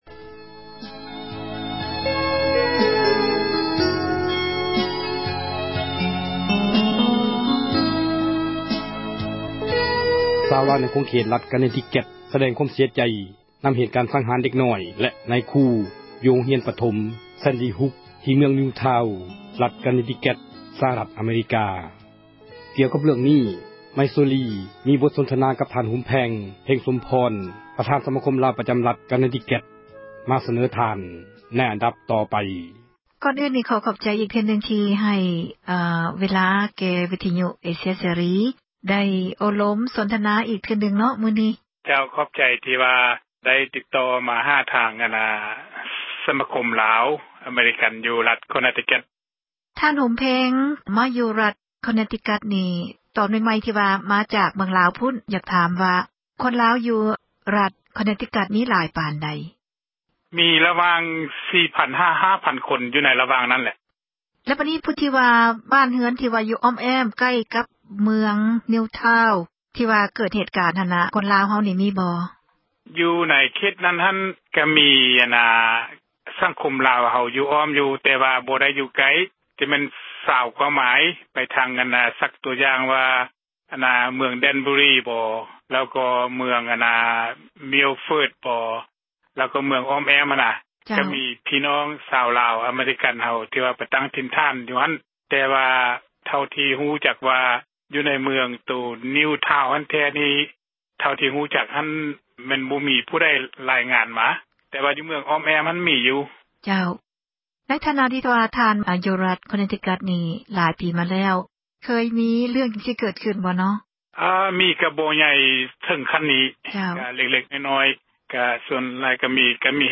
ສົນທະນາ